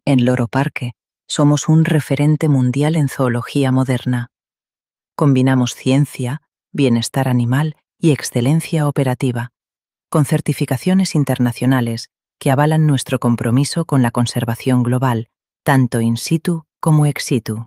Muestra de voces con IA
Voces femeninas
Elegante, sensual y suave